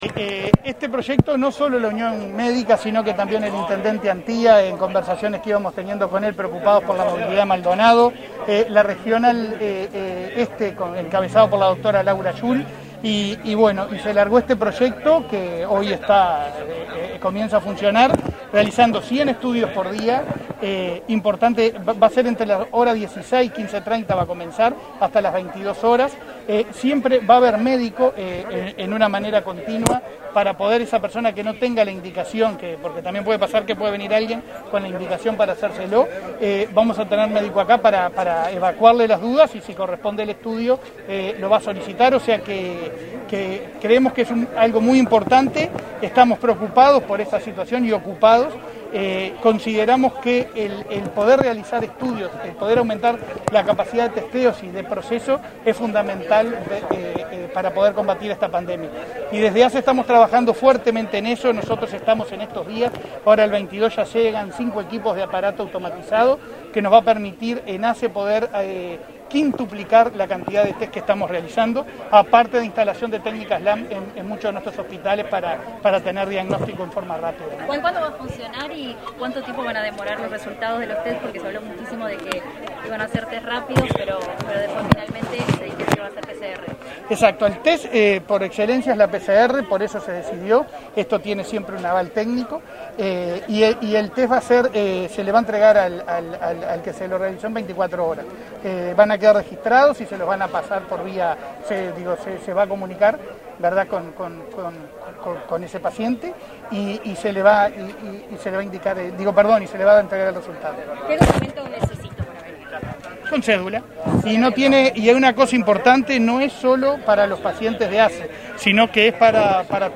Declaraciones de Leonardo Cipriani, presidente de ASSE